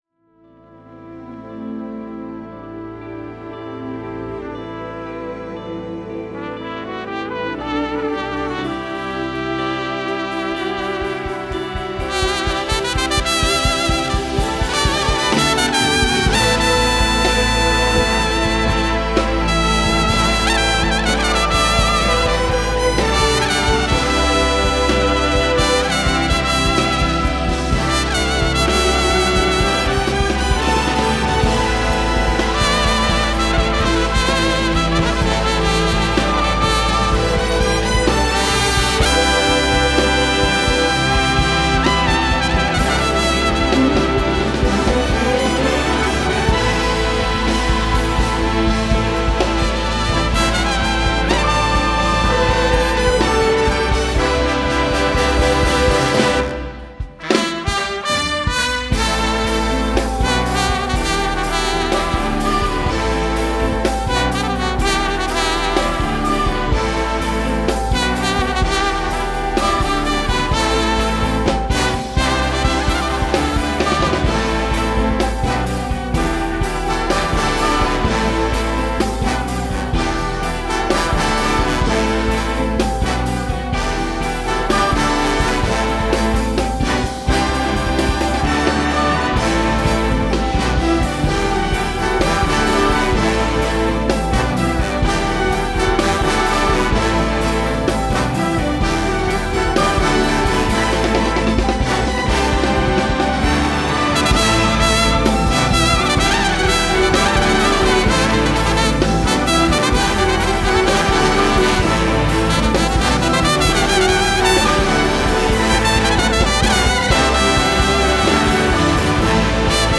Hereby an audio flashback from that show: